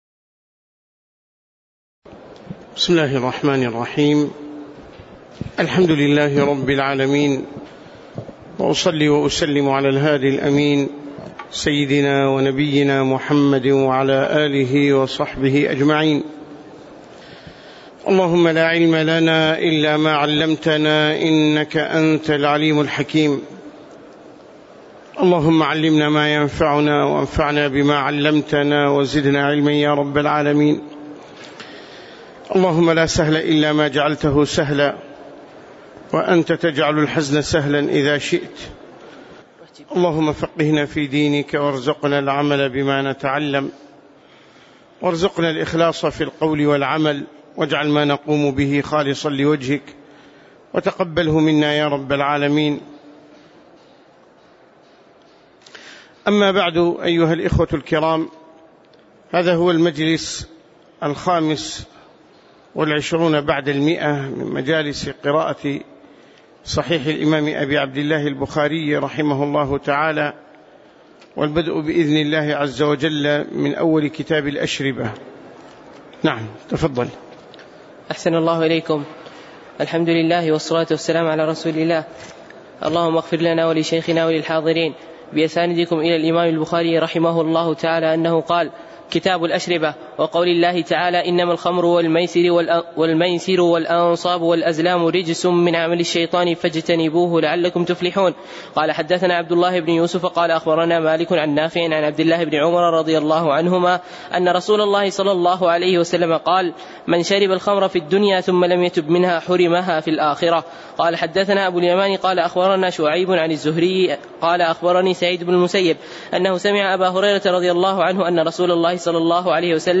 تاريخ النشر ٢٧ شعبان ١٤٣٨ هـ المكان: المسجد النبوي الشيخ